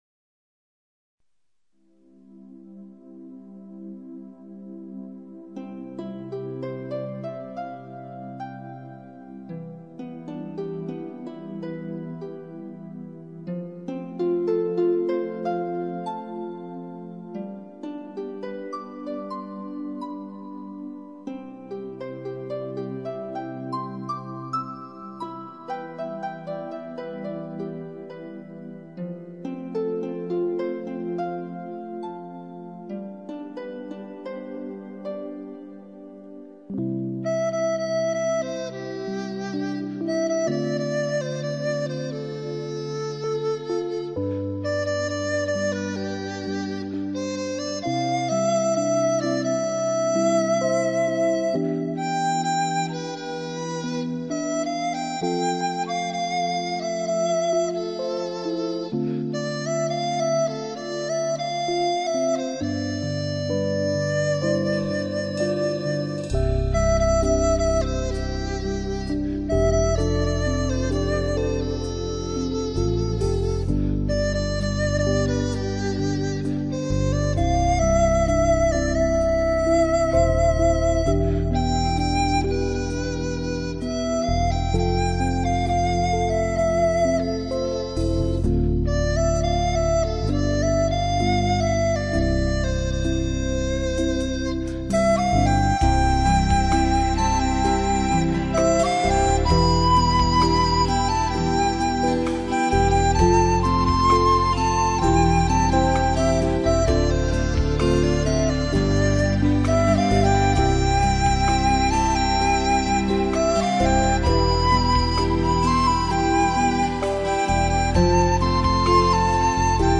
清脆通透的笛音，传来声声竹韵。
再仔细听听这些曲子，的确都具有朴实无华，明丽清纯的特色。
这种“竹韵”与吉他以及电子合成器的粘合力很强。